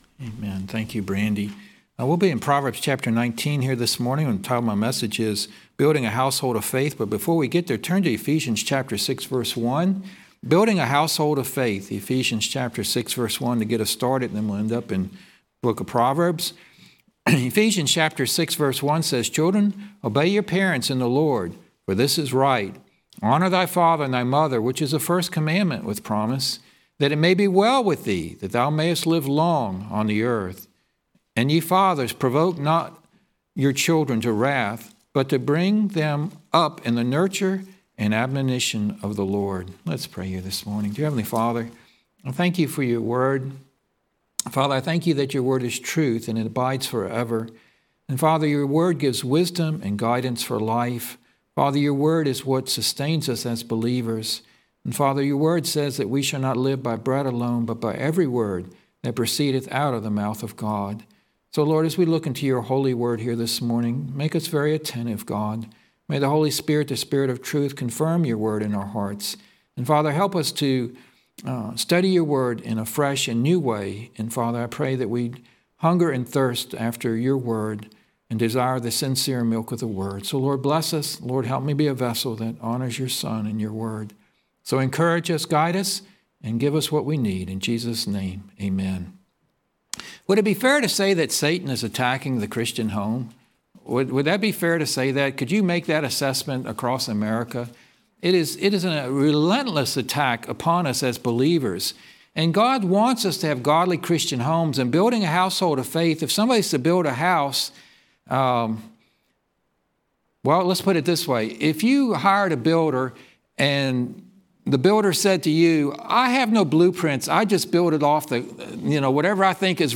Proverbs 19:13-29 Service Type: Morning Service Watch on YouTube .